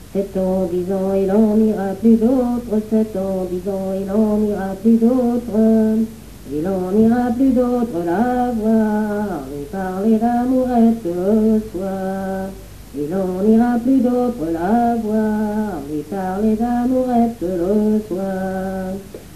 Genre énumérative
Catégorie Pièce musicale inédite